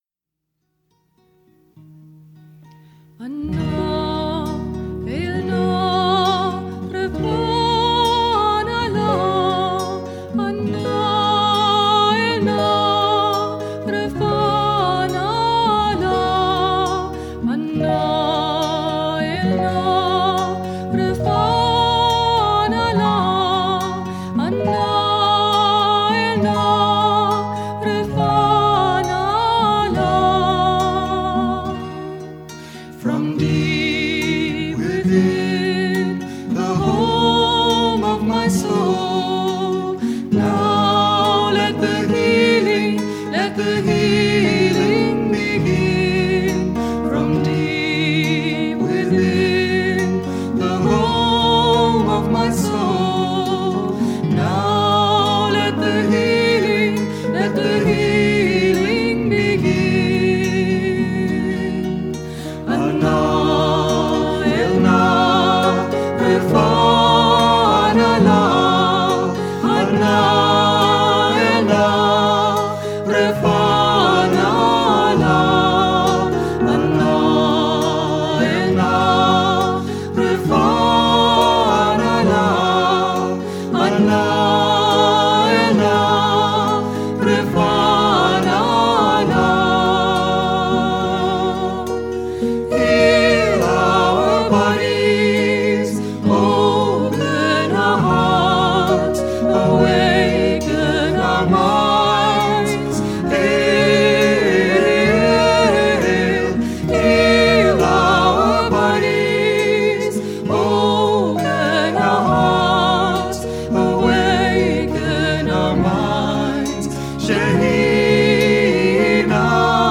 1. Devotional Songs
Minor (Natabhairavi) 6 Beat  Men - 5 Pancham  Women - 2 Pancham
Minor (Natabhairavi)
6 Beat / Dadra
Slow